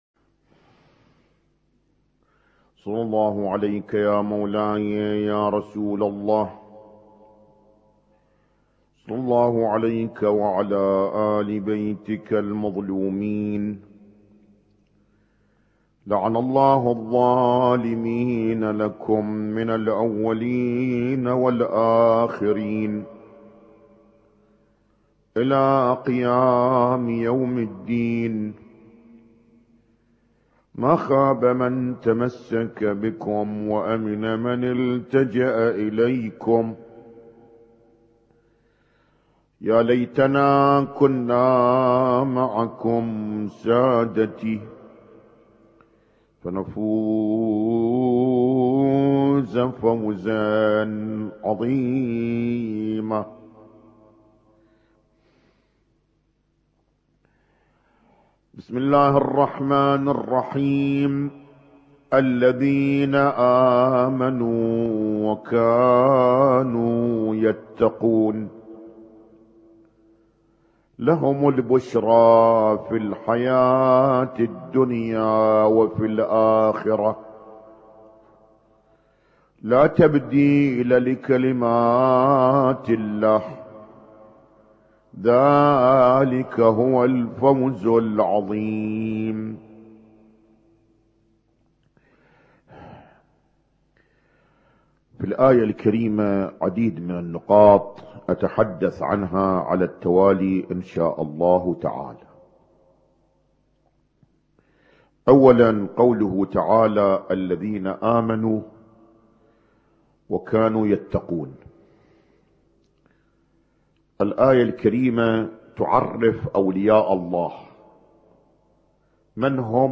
اسم التصنيف: المـكتبة الصــوتيه >> المحاضرات >> المحاضرات الاسبوعية ما قبل 1432